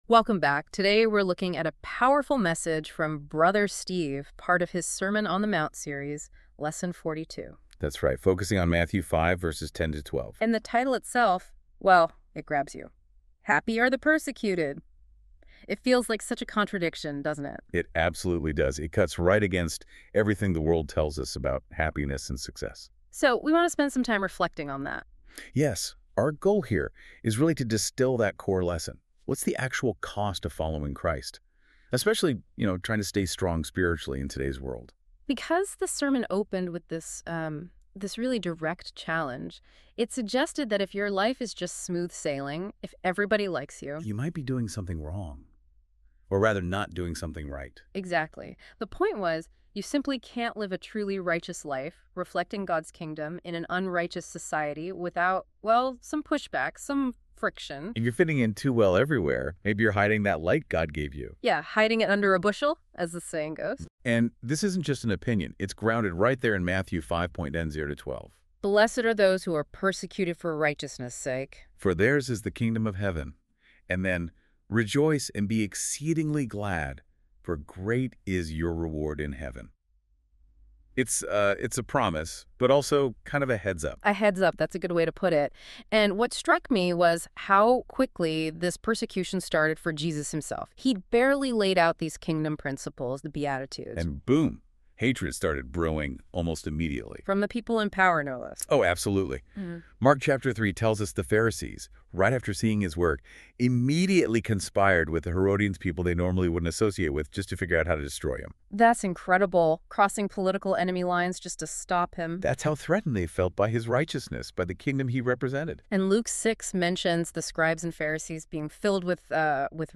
Sermons | NEW LIFE FAMILY WORSHIP CENTER